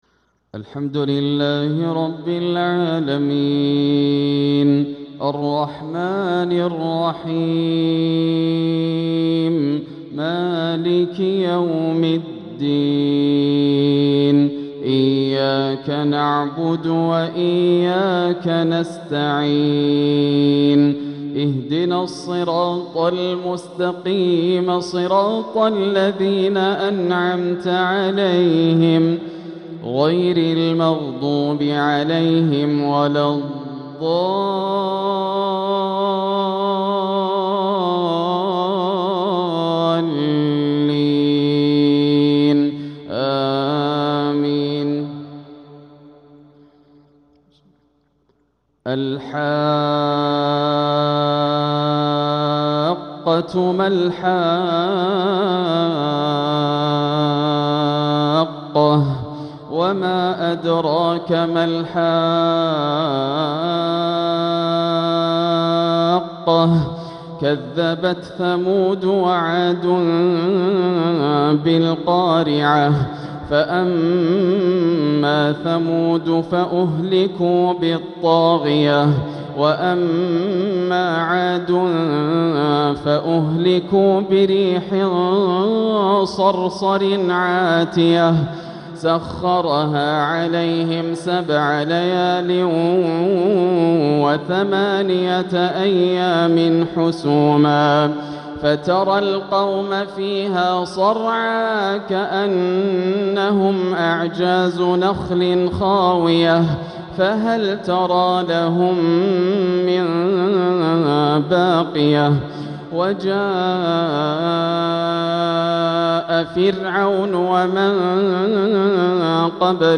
تلاوة خاشعة من سورة الحاقة | عشاء الخميس 6-2-1447هـ > عام 1447 > الفروض - تلاوات ياسر الدوسري